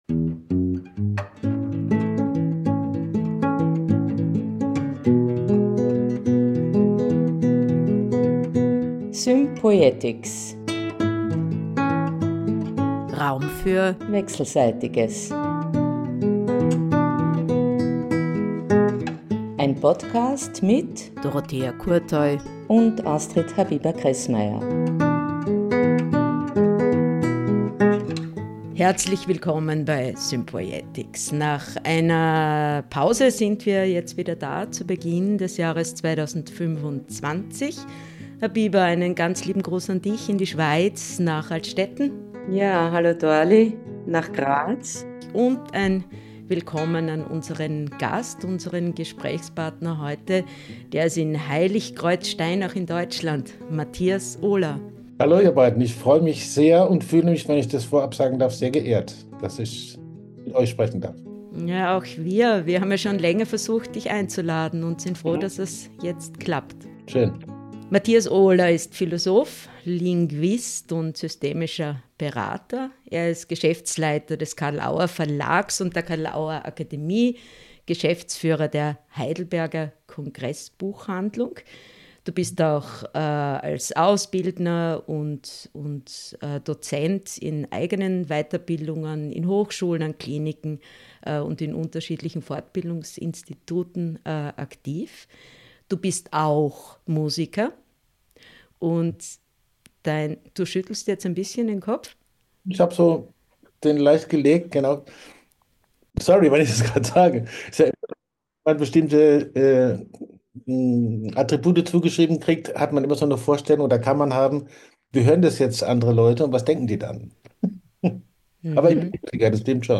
Es hat sogar den Milanen gefallen, aber hör selbst!